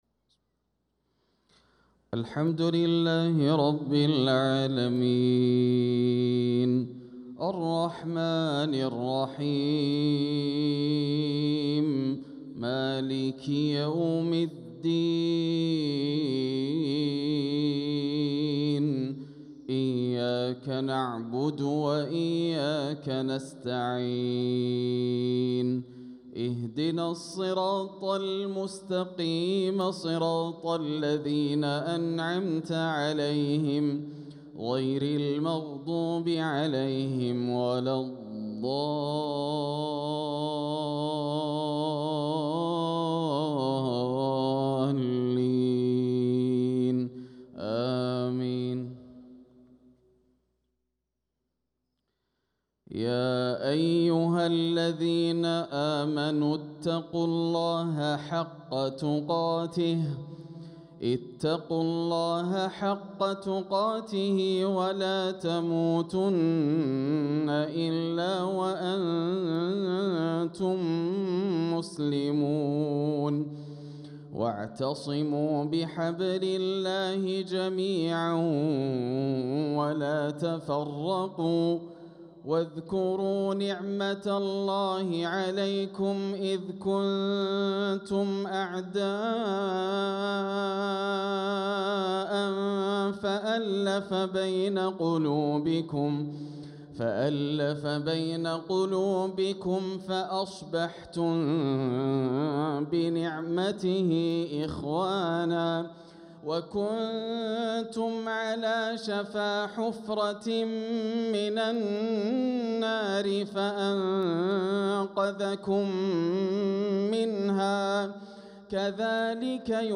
صلاة المغرب للقارئ ياسر الدوسري 2 ربيع الأول 1446 هـ